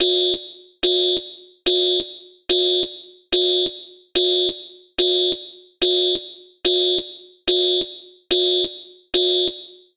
فایل صوتی آژیر فایل صوتی آژیر نرم افزار تحت ویندوز نمایش لاگ دستگاه نرم افزار تحت ویندوز نمایش لاگ دستگاه نرم افزار اندرویدی نمایش لاگ دستگاه نرم افزار اندرویدی نمایش لاگ دستگاه دفترچه راهنمای راه اندازی دستگاه دزدگیر دفترچه راهنمای راه اندازی دستگاه دزدگیر